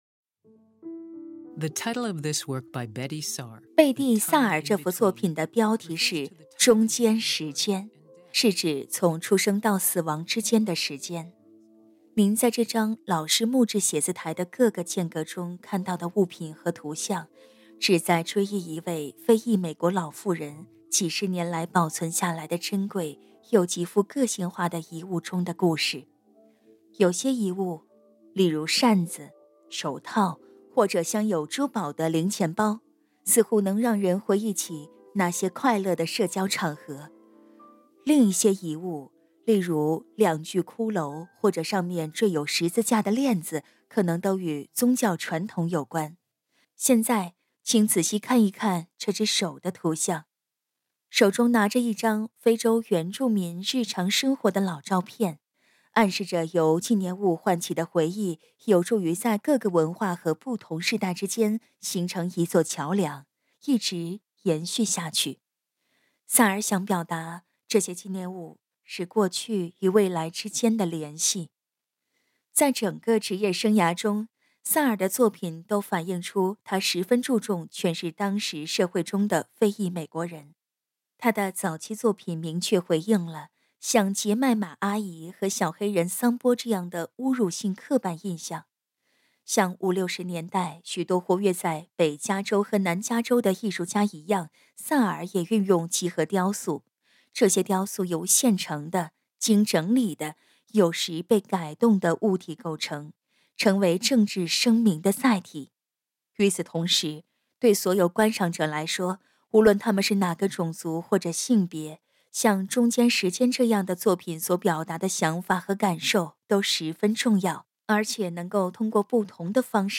Audio Stories